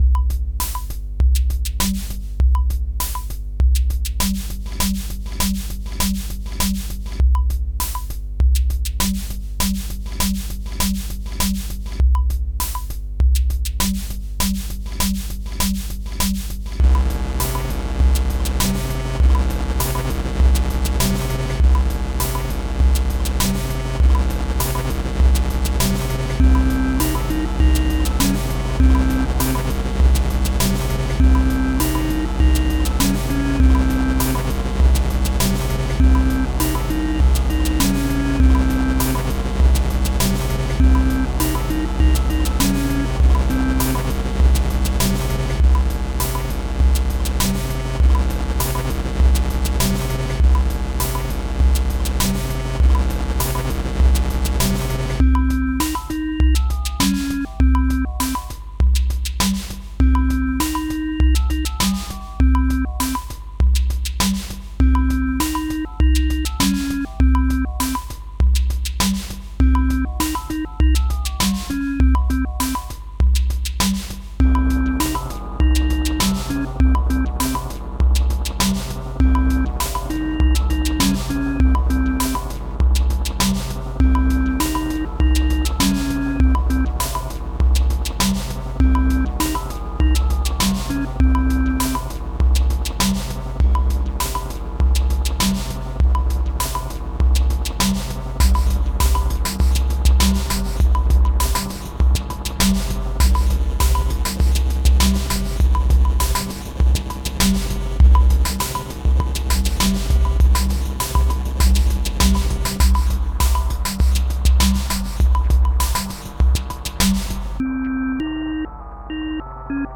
Electro-rock
rock
Sonidos: Música